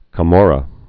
(kə-môrə, -mŏrə)